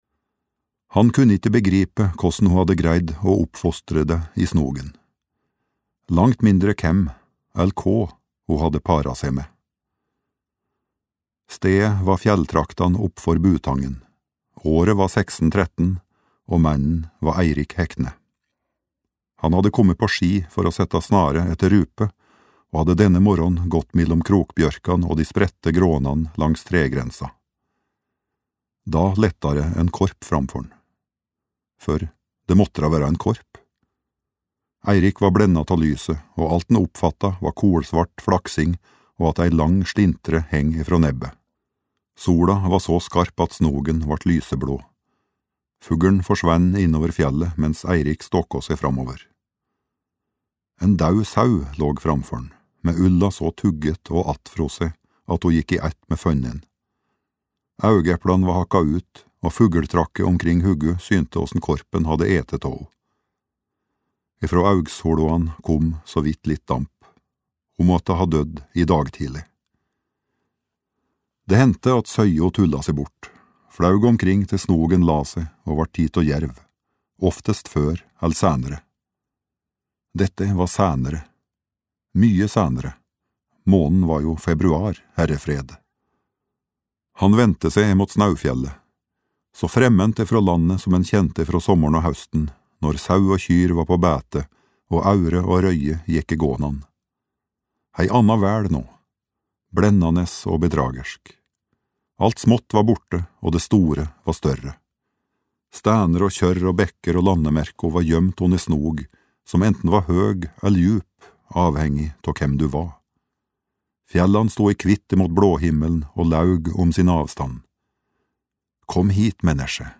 Skråpånatta (lydbok) av Lars Mytting